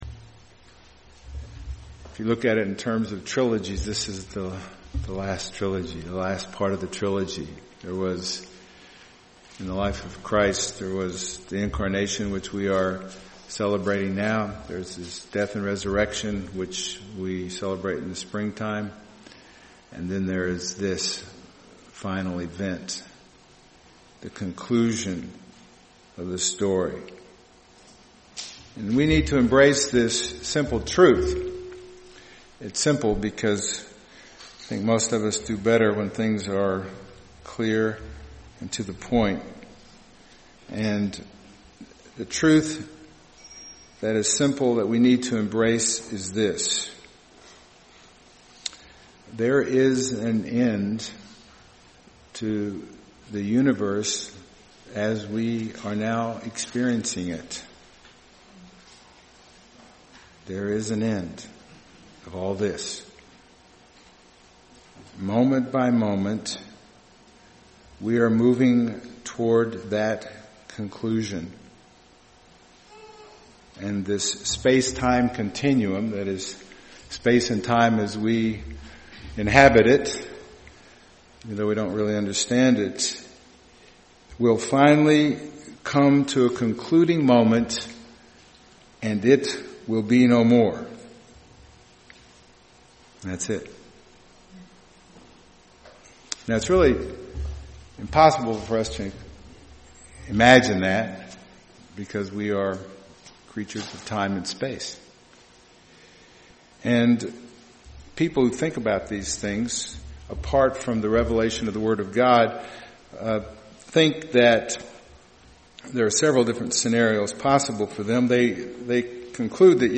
Fourth Sunday of Advent (2014) – *Introduction is missing